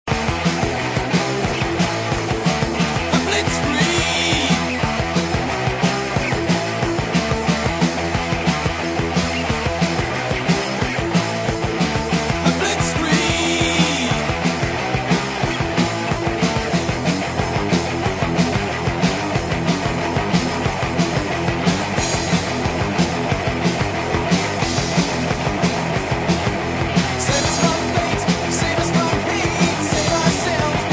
pretty but un-forceful vocals